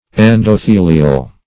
Search Result for " endothelial" : Wordnet 3.0 ADJECTIVE (1) 1. of or relating to or located in the endothelium ; The Collaborative International Dictionary of English v.0.48: Endothelial \En`do*the"li*al\, a. (Anat.)